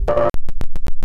Abstract Rhythm 05.wav